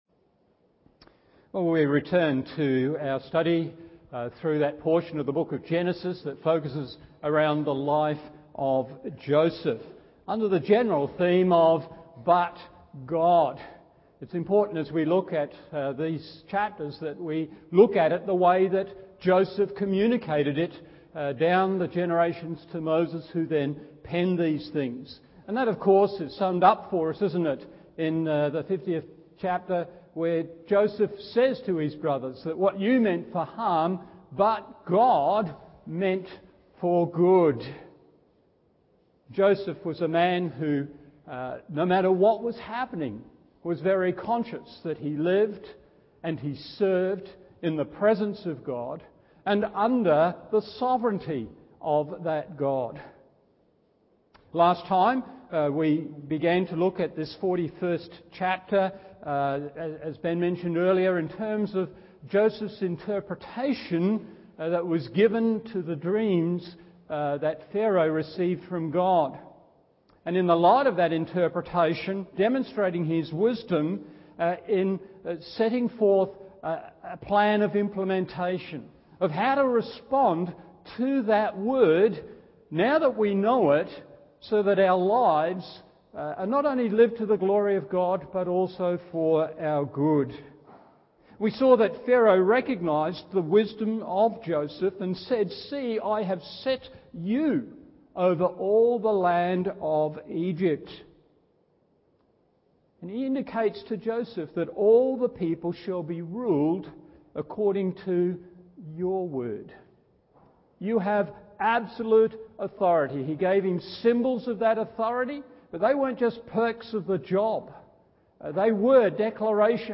Morning Service Genesis 41:45 & 50,52 1.